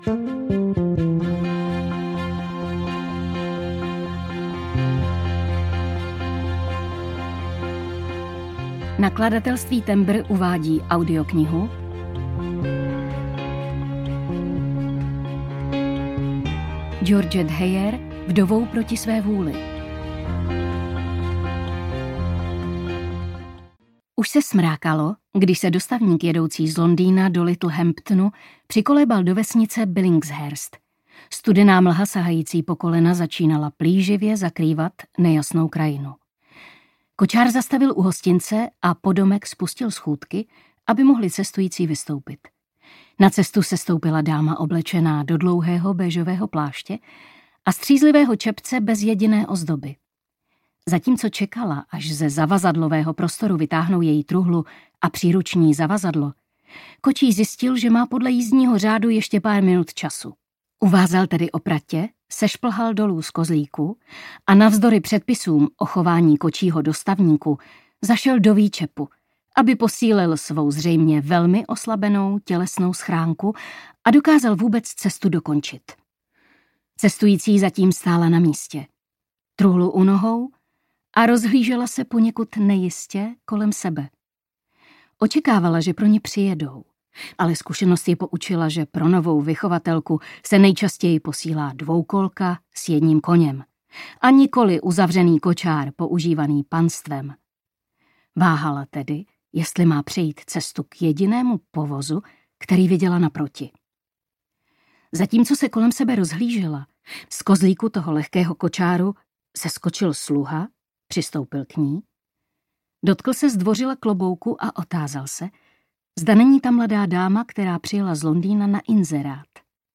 Audio knihaVdovou proti své vůli
Ukázka z knihy